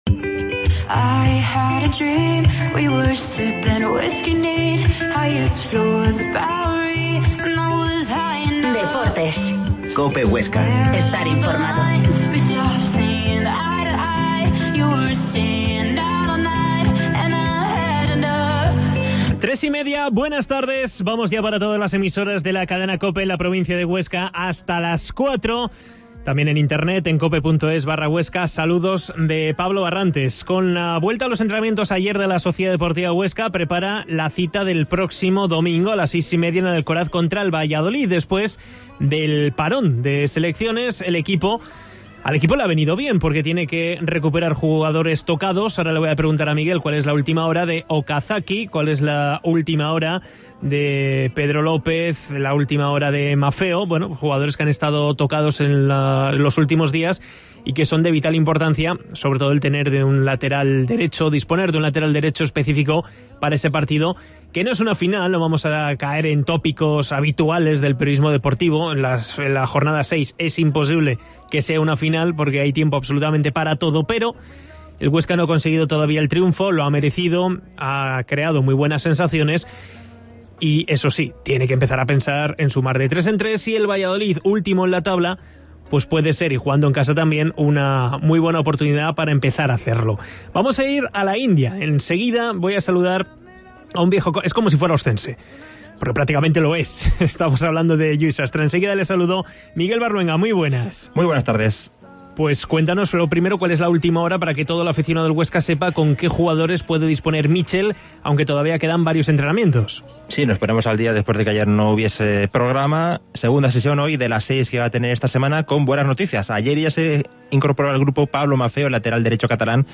Entrevista a Lluis Sastre desde la India